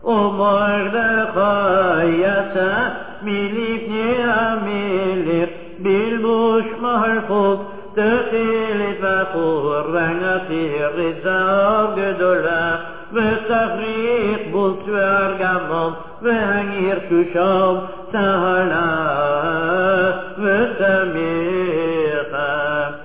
The following verses are sung by the kahal, and repeated by the Chazzan: